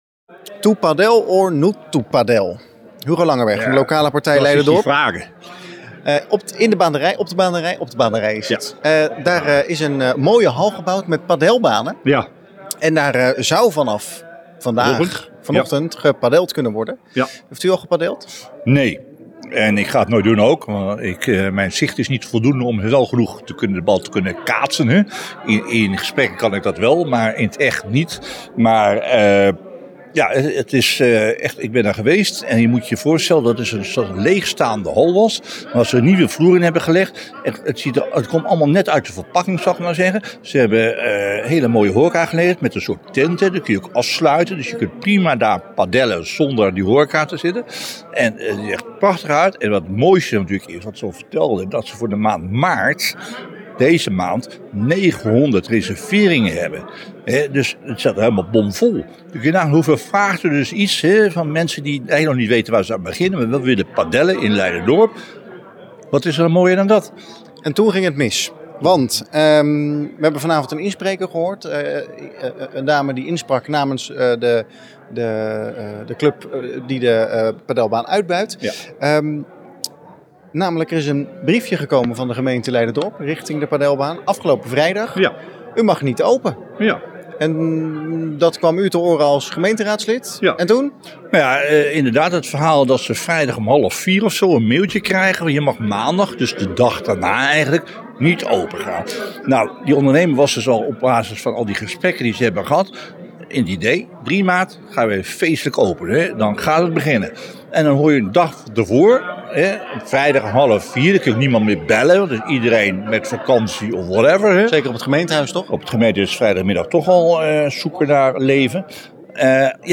Raadslid Hugo Langenberg in gesprek